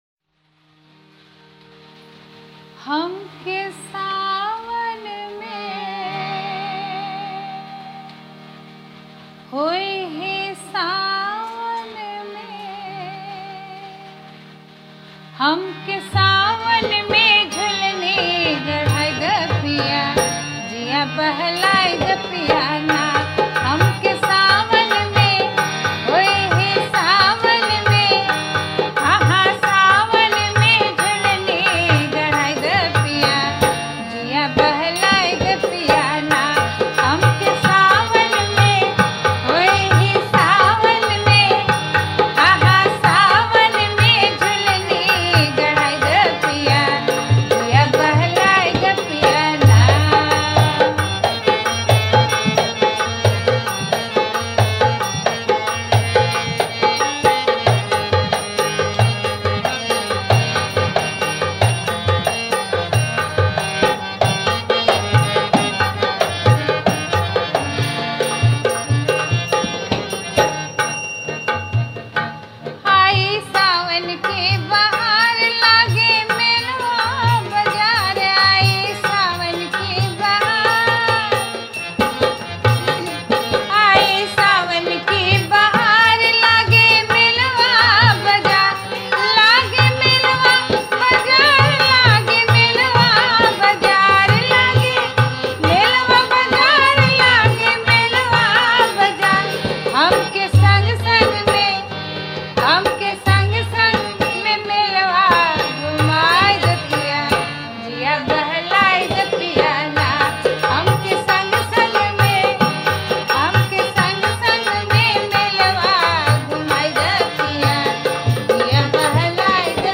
Rajasthani Songs
Kajri